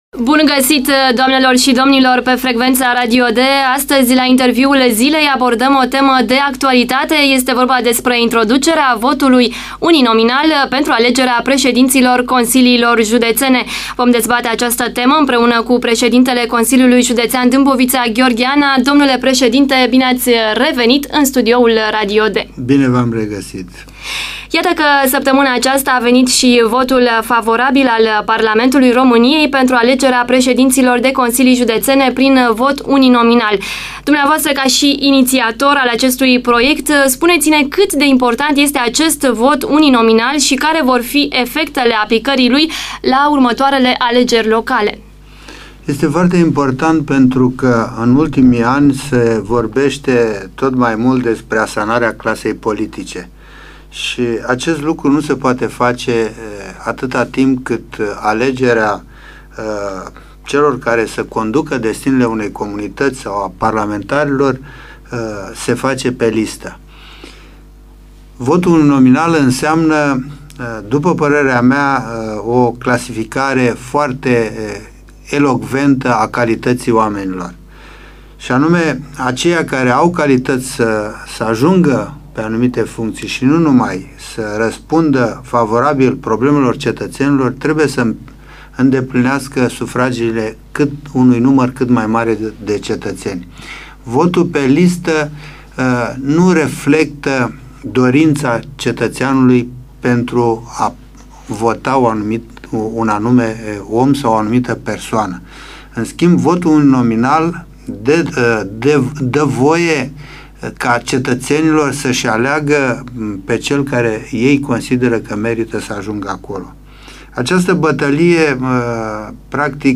Gheorghe Ana - Interviu Radio D:6 martie 2008
Gheorghe Ana - Interviu Radio D:6 martie 2008 Dată: 07.03.2008 Interviul Domnului Gheorghe Ana, Pre ş edintele Consiliului Judeţean Dâmboviţa, acordat postului Radio D în ziua de 6 martie 2008: format audio ~ 40 MB Înapoi